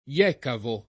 jekavo → iecavo
jekavo [ L$ kavo ]